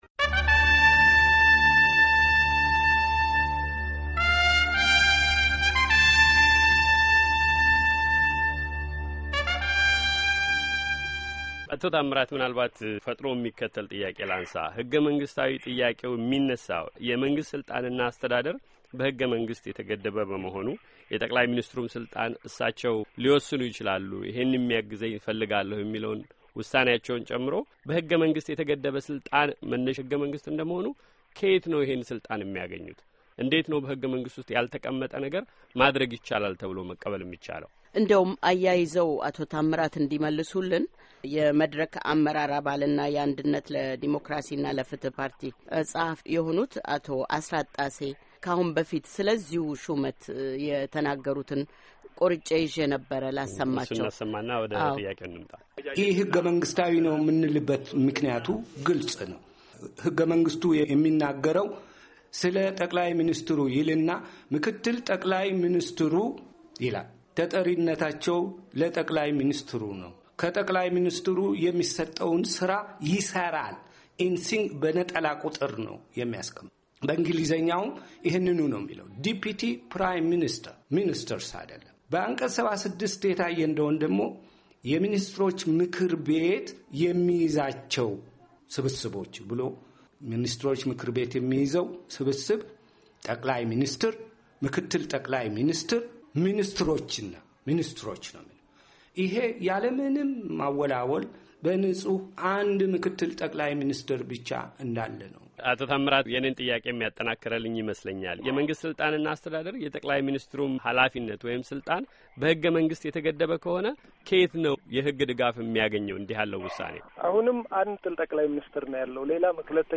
ውይይት፤ በአዲሱ የኢትዮጵያ ካቢኔ አሿሿም ዙሪያ